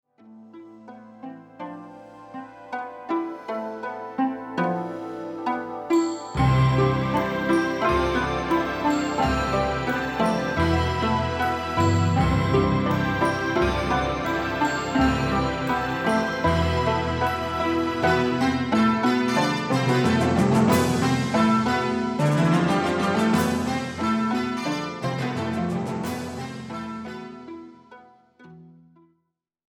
Recorded spring 1986 at the Sinus Studios, Bern Switzerland